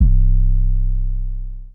From time 808.wav